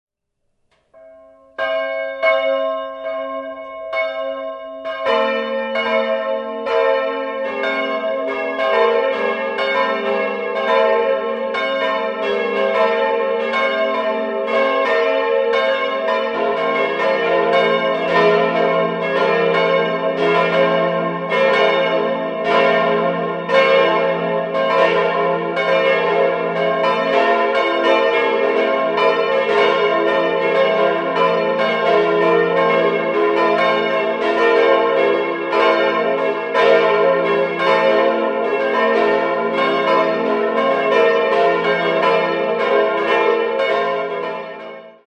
Christkönigsglocke f' 1.330 kg 1.430 mm 1948 Bochumer Verein Herz-Marien-Glocke as' 785 kg 1.200 mm 1948 Bochumer Verein Leonhardsglocke b' 490 kg 1.070 mm 1948 Bochumer Verein Kleine Glocke des'' 310 kg 900 mm 1948 Bochumer Verein